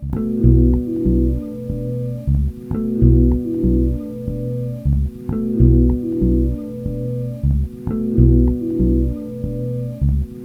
Můžu sem zkusit nahodit nějaký ambientnější puzzle-kousky (starý struny), co jsem dřív nahrával.
Kdyby to mělo být polepené z takových zvukových ploch jenom z basy, tak to moc neomezuje fantazii, ale ba naopak.